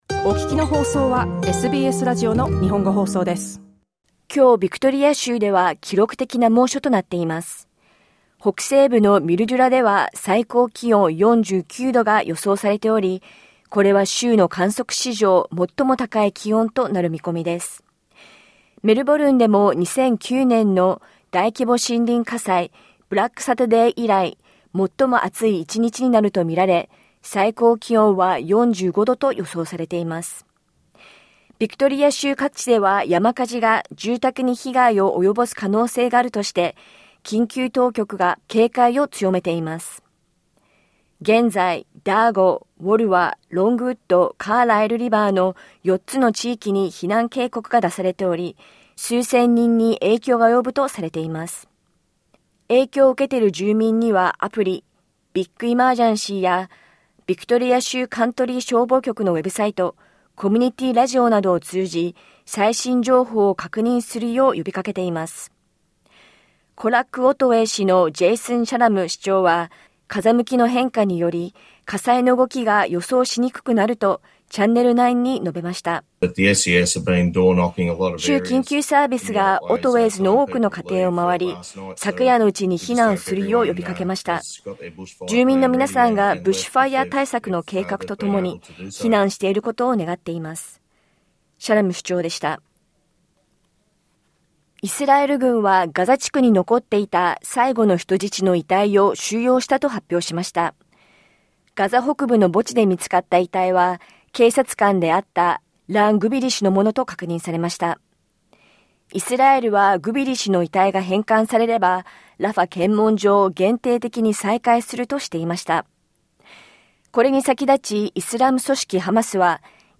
SBS日本語放送ニュース1月27日火曜日